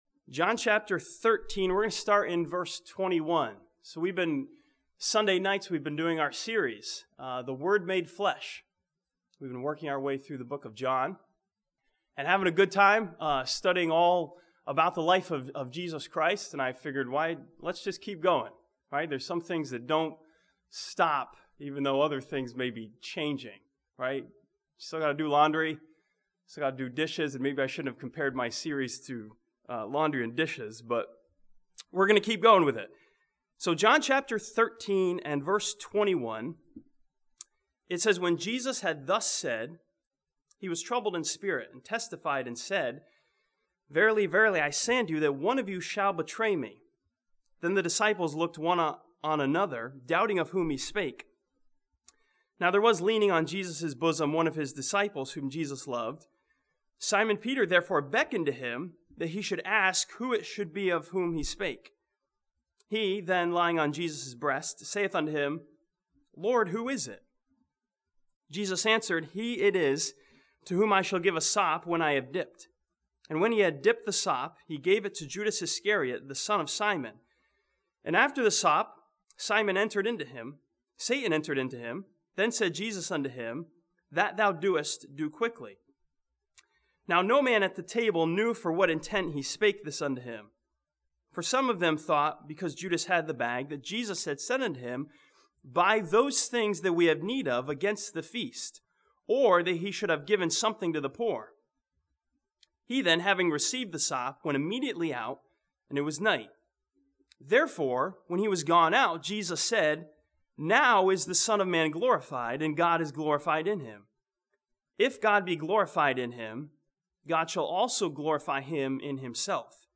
This sermon from John chapter 13 looks at the program of events Jesus laid out for the disciples and finds our role in His program.